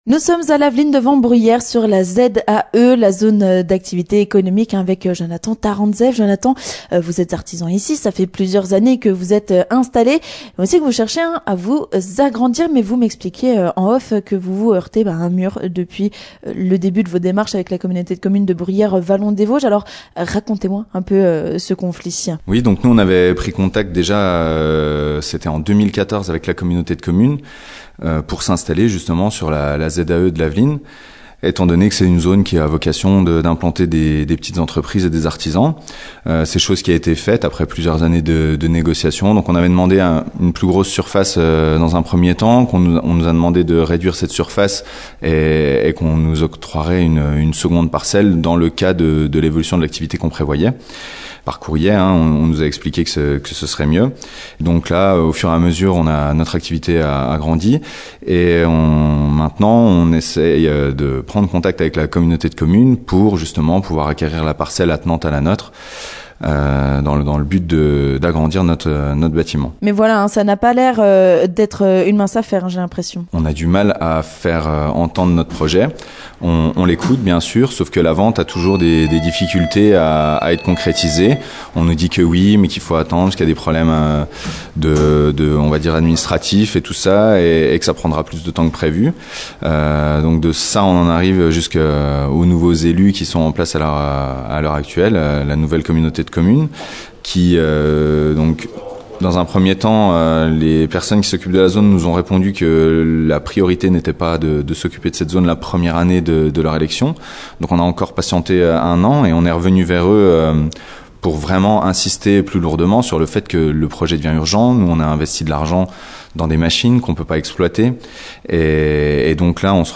Nous sommes allés à sa rencontre pour voir ce que cachait ce dossier. Et nous avons également rencontré d'autres artisans ou commerçants désabusés... Plusieurs sont en conflit avec la Communauté de Communes de Bruyères Vallons des Vosges, propriétaire des terrains.